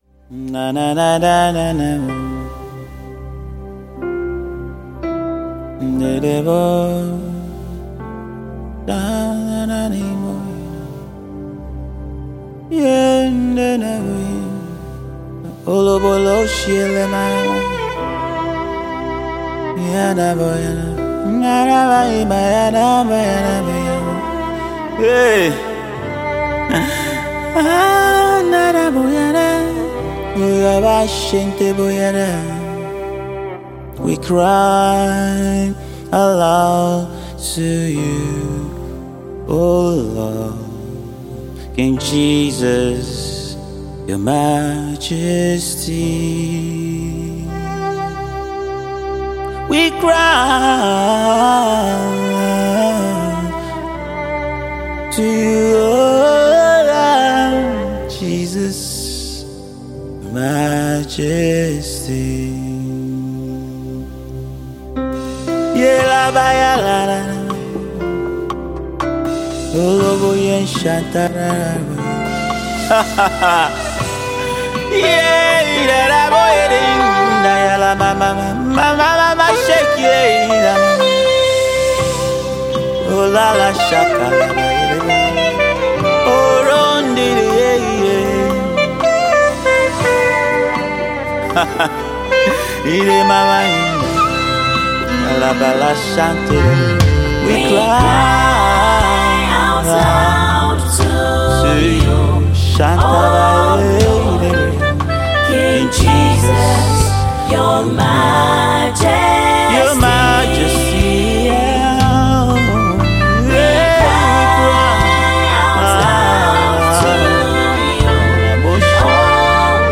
Nigerian US-based ace gospel music artiste and songwriter